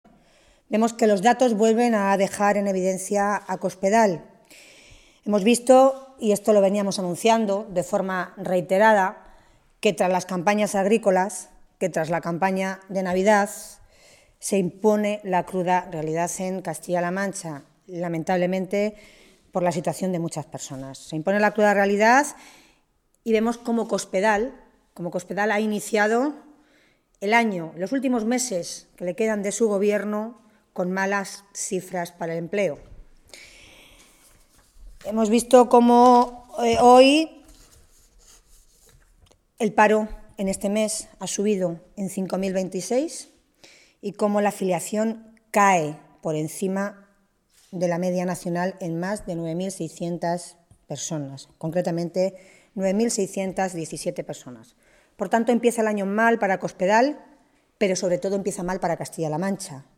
Tolón realizaba estas declaraciones en una rueda de prensa en las que valoró los datos del paro del mes de enero que se han conocido hoy.
Cortes de audio de la rueda de prensa